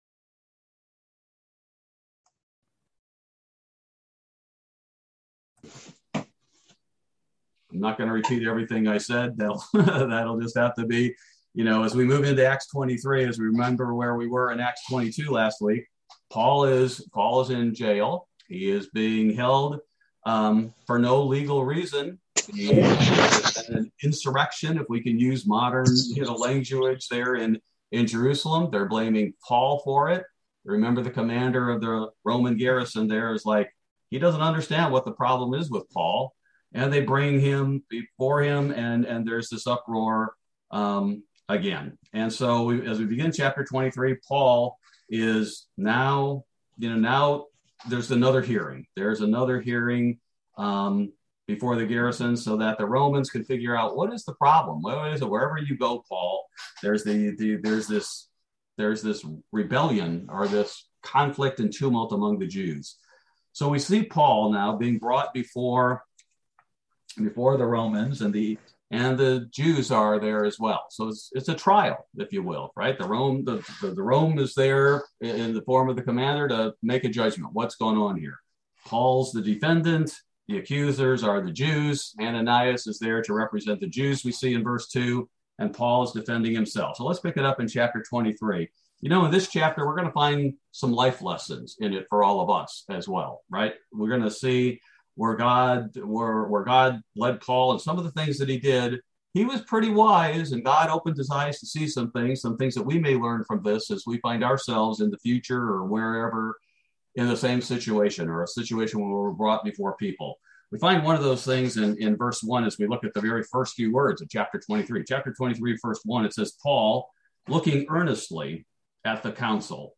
Bible Study: January 5, 2022